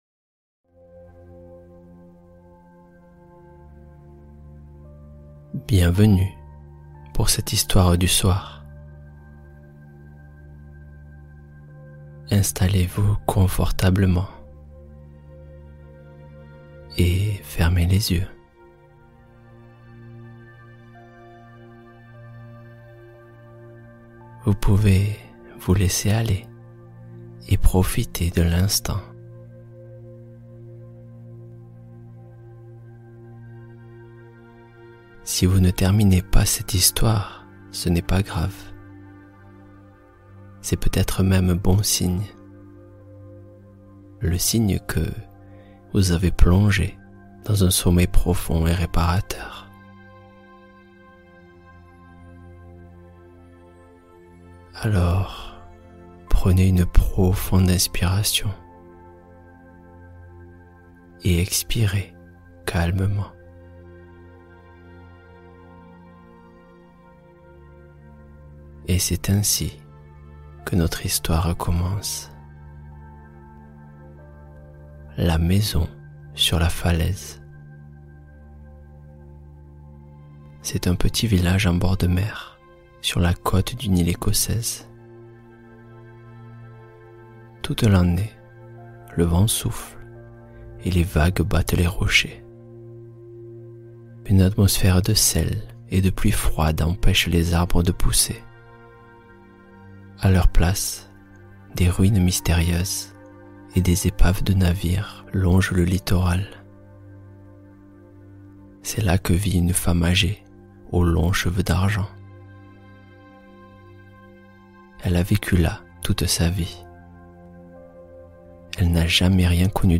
L'art de méditer La Maison sur la Falaise : Conte apaisant pour une nuit sereine Feb 26 2026 | 00:37:42 1x 00:00 / 00:37:42 Subscribe Share Spotify RSS Feed Share Link Embed